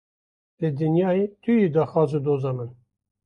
Wekî (IPA) tê bilêvkirin
/dɑːxˈwɑːz/